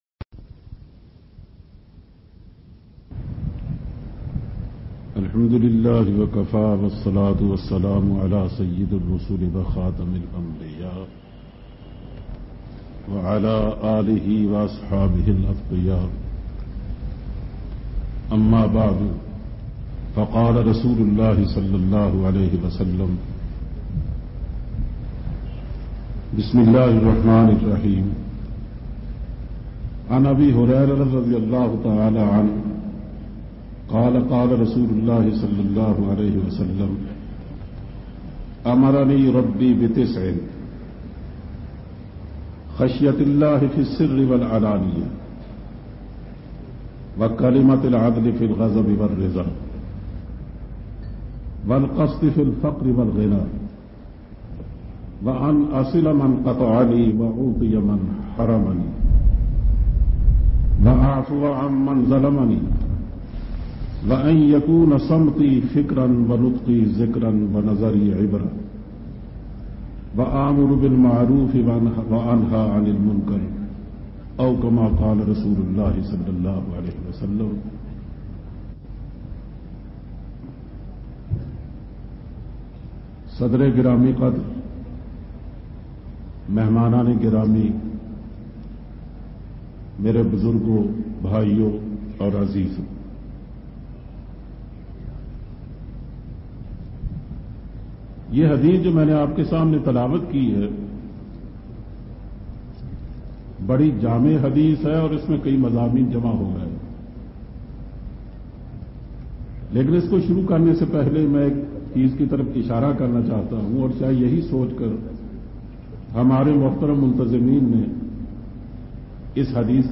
Dars Hadith